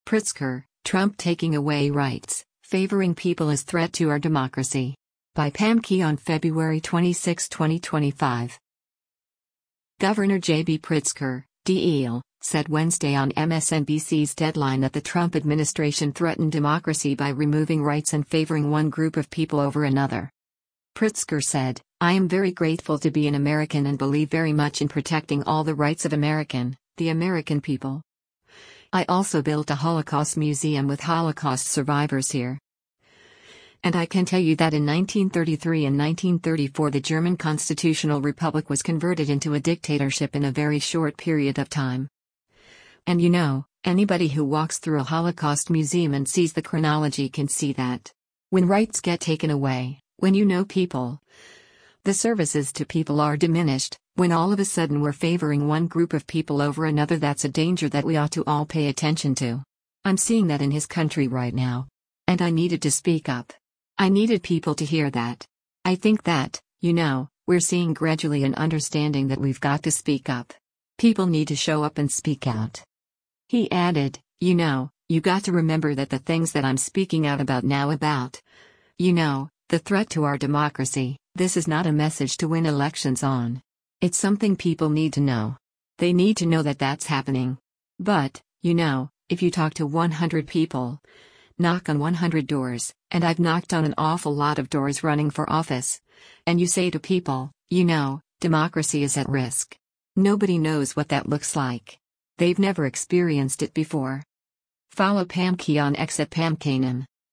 Governor JB Pritzker (D-IL) said Wednesday on MSNBC’s “Deadline” that the Trump administration threatened democracy by removing rights and favoring one group of people over another.